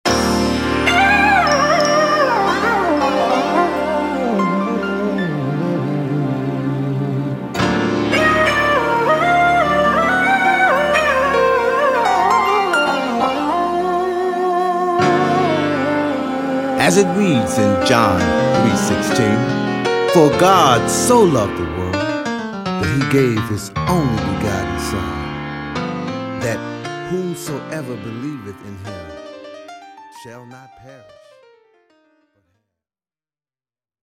Gospel jazz